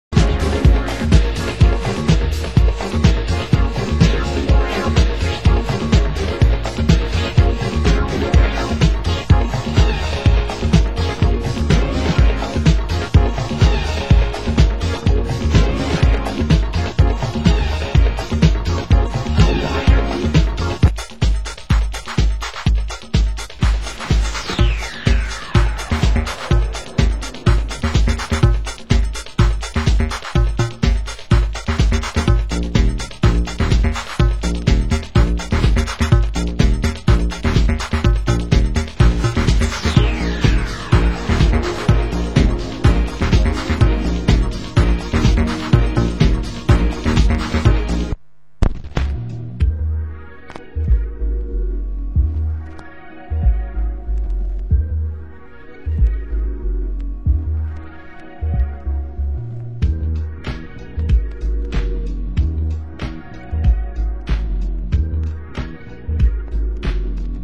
Genre: US House